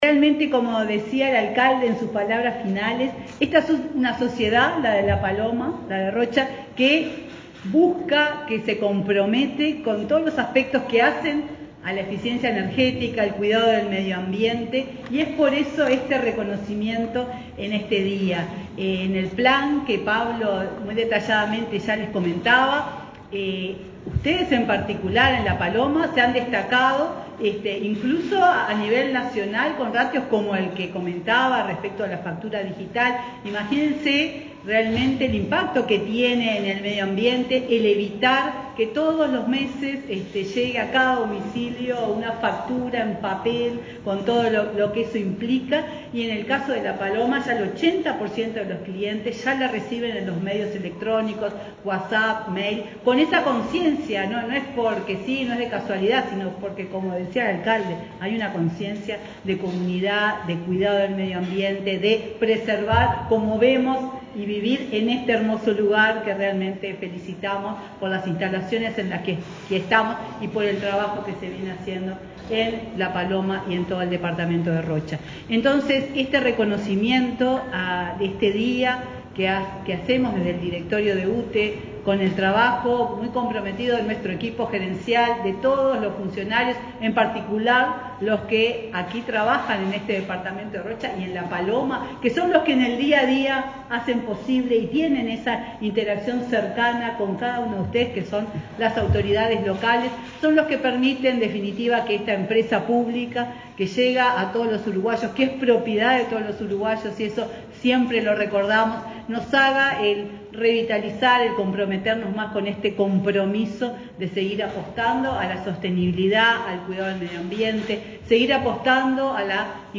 Palabras de la presidenta de UTE, Silvia Emaldi
La presidenta de la UTE, Silvia Emaldi, participó, este viernes 23 en el departamento de Rocha, en el acto de reconocimiento al balneario La Paloma